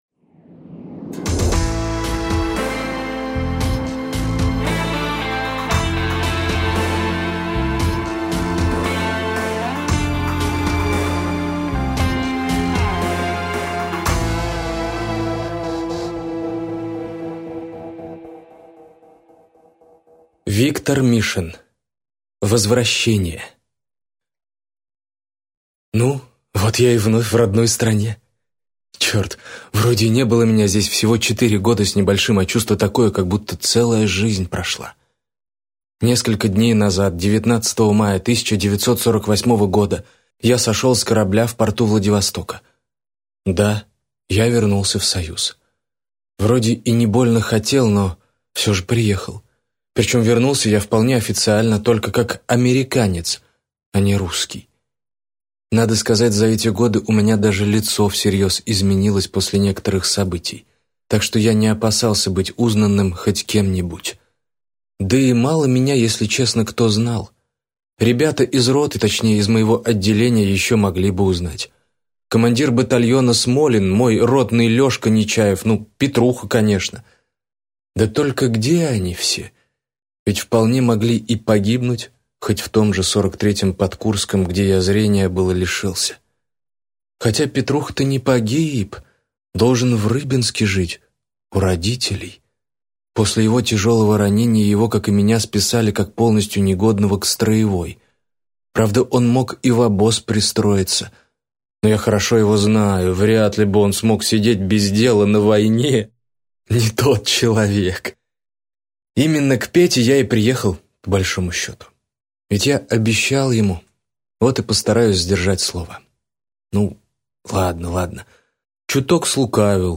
Аудиокнига Возвращение | Библиотека аудиокниг